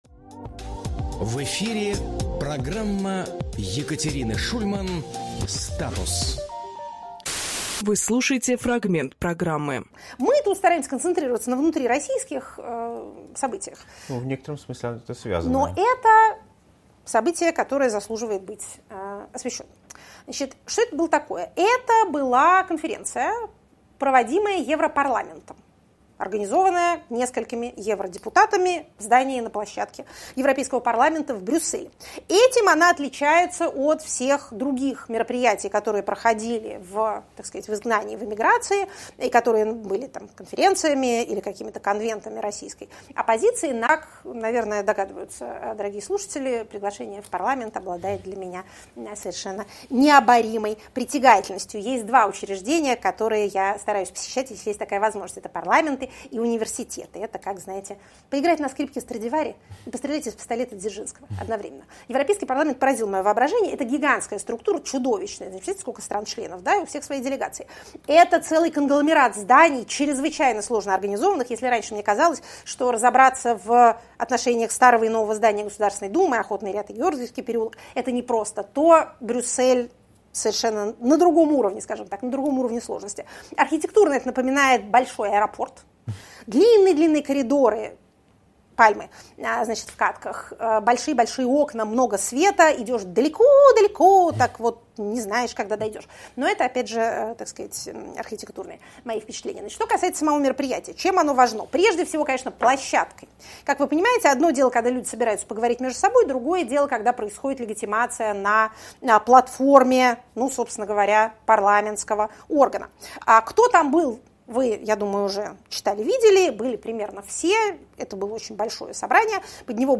Максим Курниковруководитель проекта «Эхо», журналист
Екатерина Шульманполитолог
Фрагмент эфира от 06.06.23